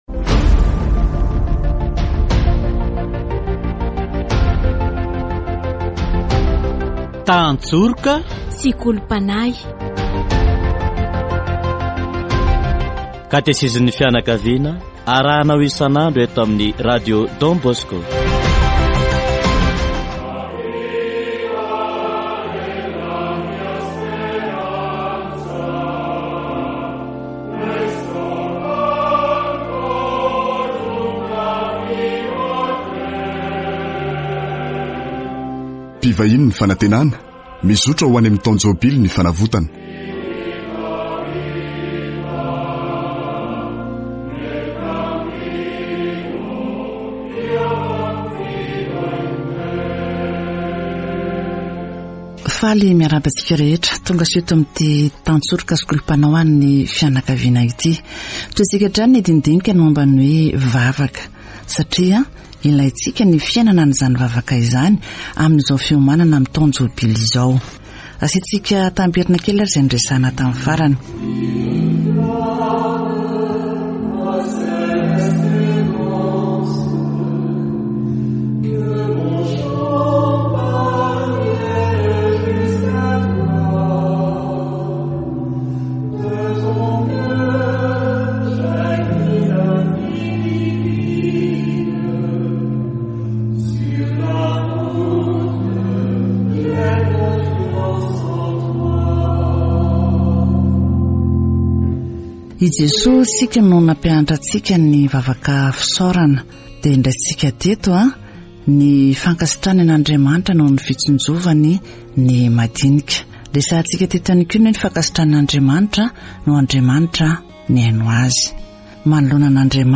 Category: Deepening faith